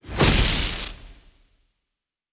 Ball lightning_Throw.wav